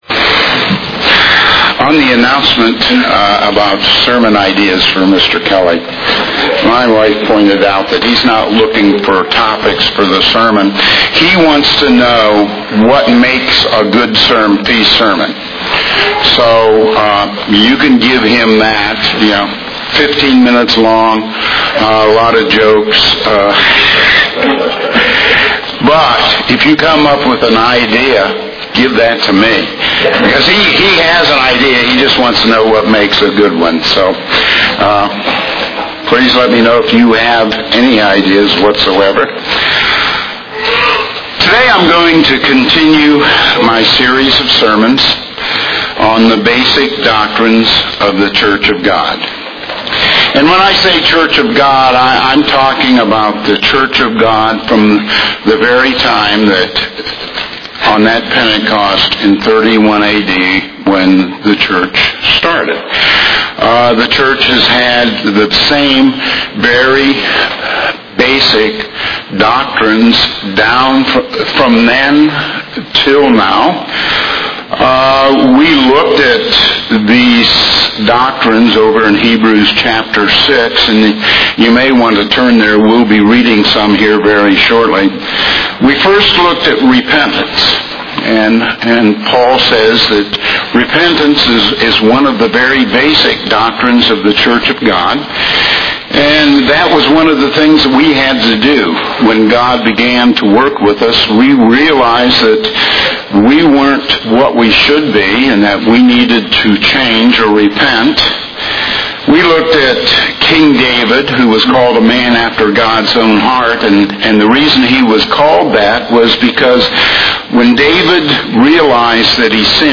This sermon continues a series on the doctrines of the Church of God.
Given in Greensboro, NC